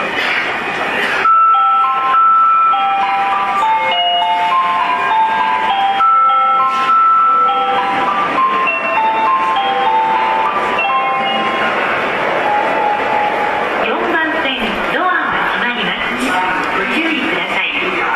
ただ高架下なのでうるさく、一部音質が悪いホームがあり、玉に瑕です。